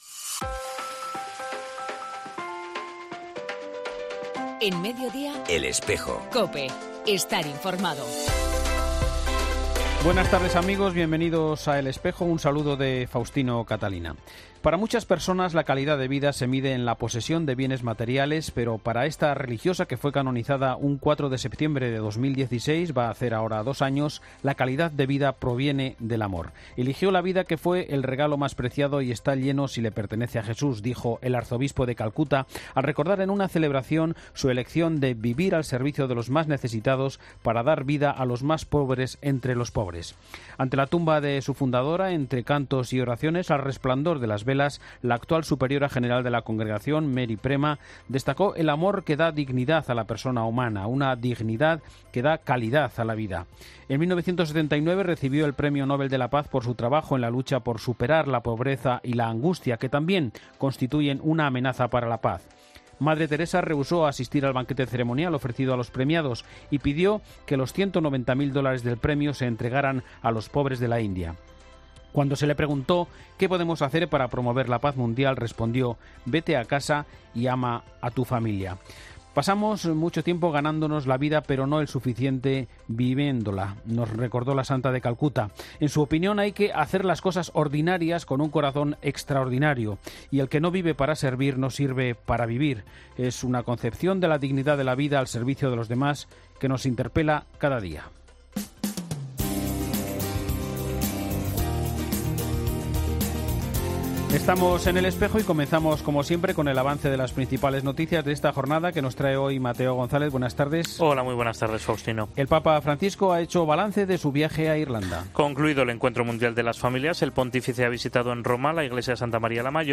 AUDIO: En 'El Espejo' de hoy hablamos con Mons. Mario Iceta, presidente de la Subcomisión Episcopal para la Familia y la Defensa de la Vida de la...